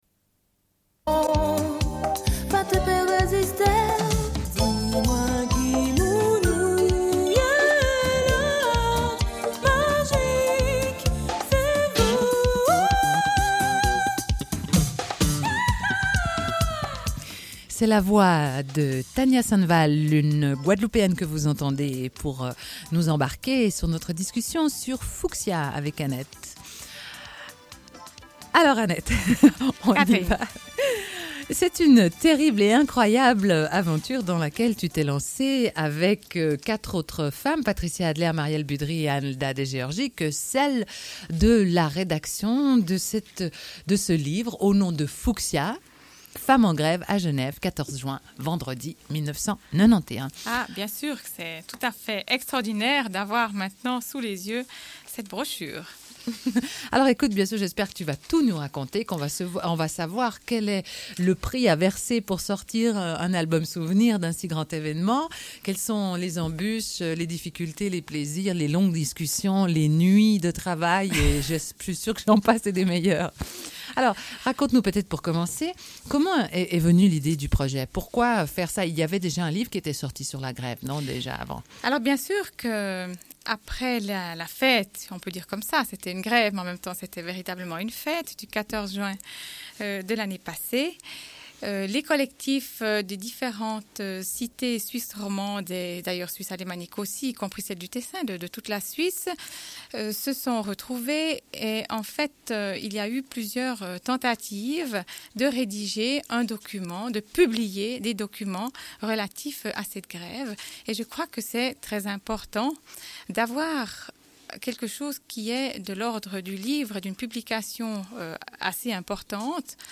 Une cassette audio, face B00:29:02
Suite de l'émission : rendez-vous avec la librairie L'Inédite en direct du Salon du livre.